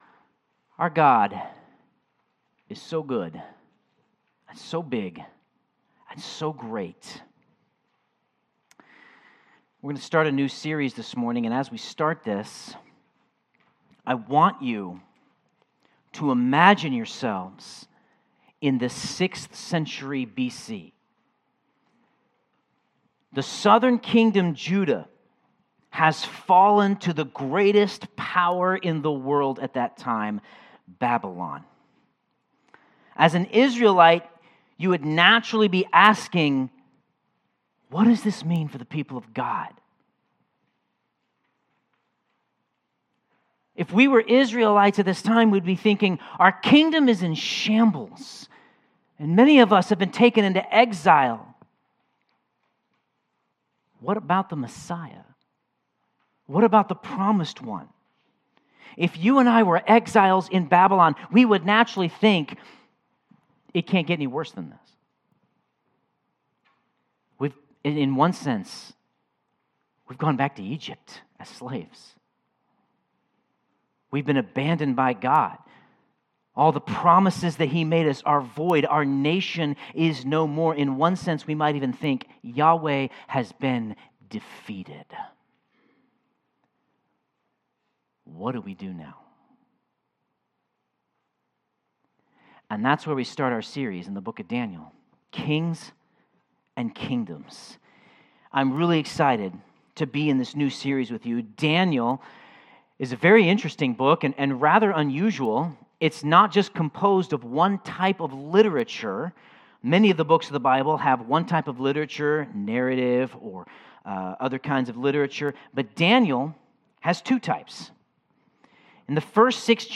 Sermon Notes God is in control in all areas of life.